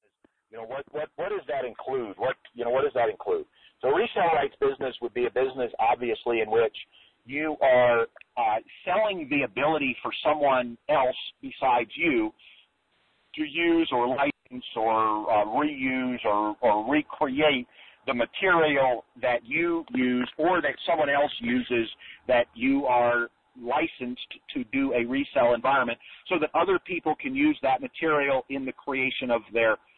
If your wanting to learn how to create a successful online business reselling products, then this audio course is ideal for you.